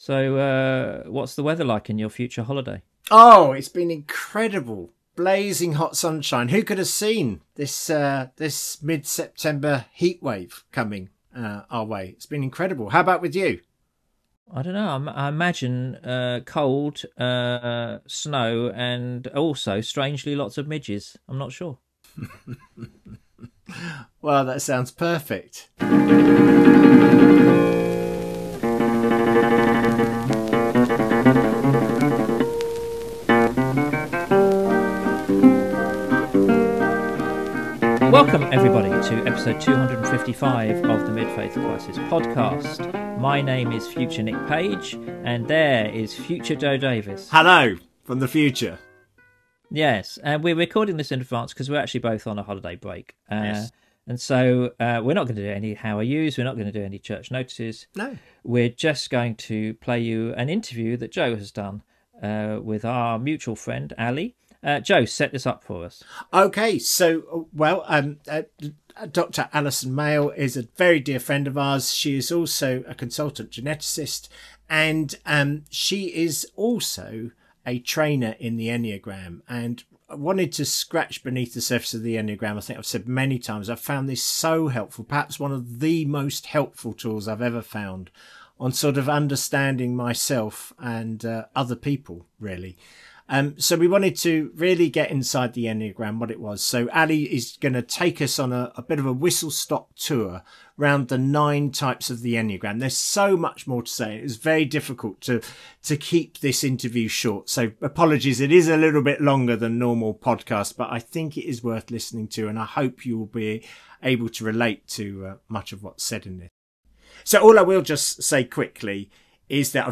Or, to put it another way, it's a conversation between a number one and a number seven, mentioning the number five and the number nine to whom they are respectively married, and pausing only to abuse a number four along the way. Some sound issues - apologies about that.